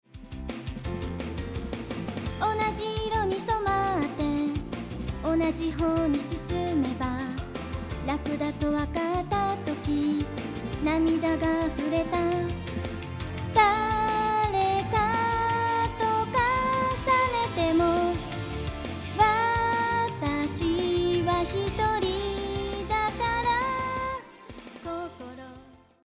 ここでは「BASIC FEMALE VOCAL」という女性ボーカル用のプリセットを使ってみました。
Unisonテクノロジーで利用する場合は、まさに実機を使って録音するのと同様、掛け録りになります。